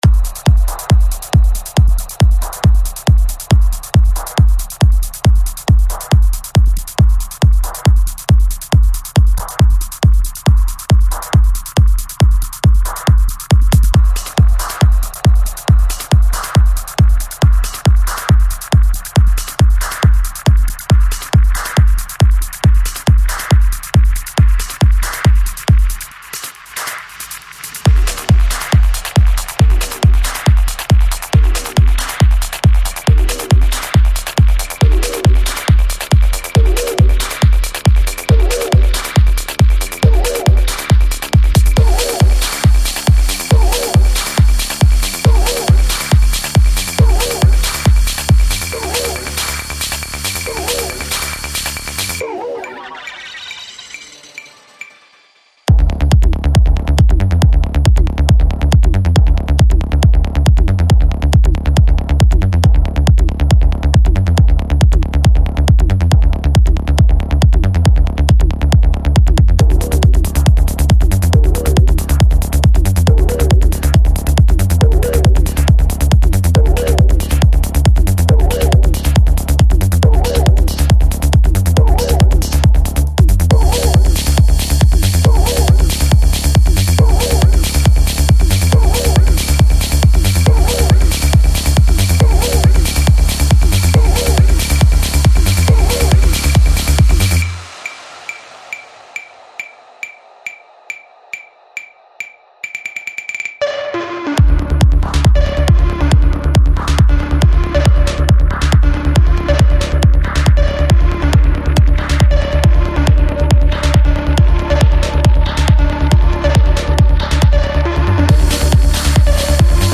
Стиль: Tech Trance